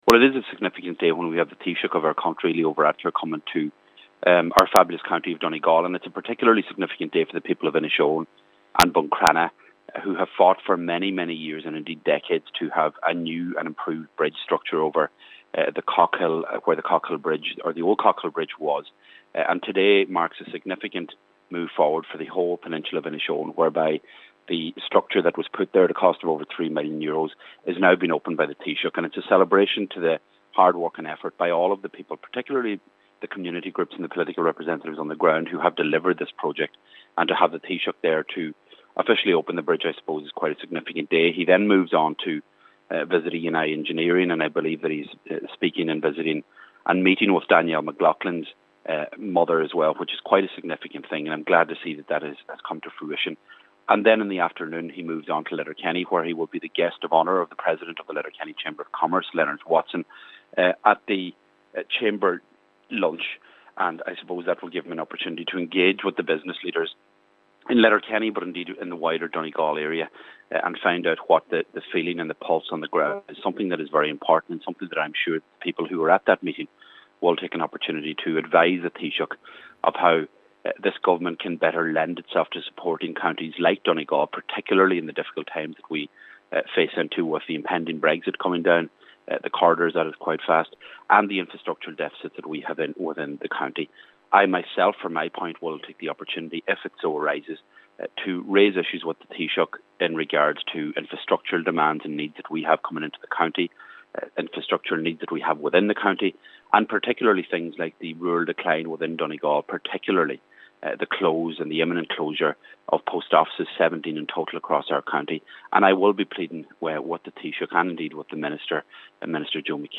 Cathaoirleach of Donegal County Council Cllr Seamus O’Domhnaill says it’s a significant day but also an opportunity to raise very serious issues facing the county: